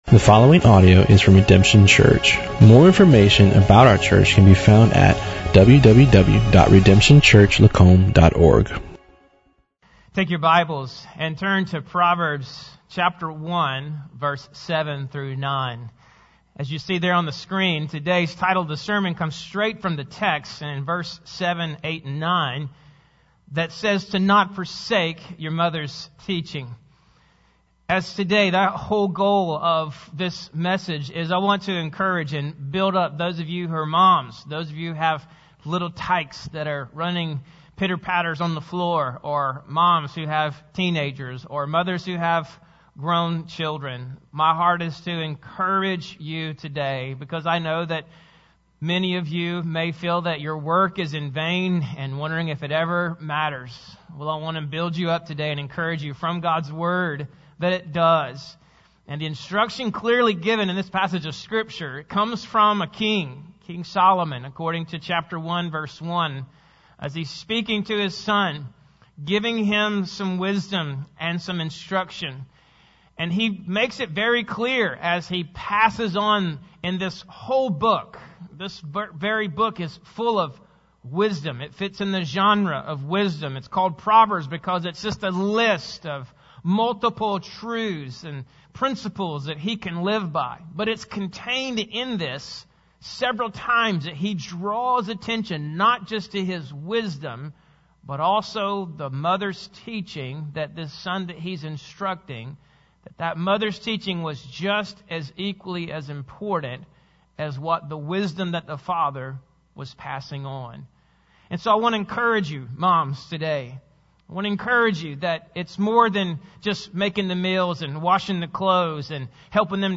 Bible Text: Proverbs 1:7-9 | Preacher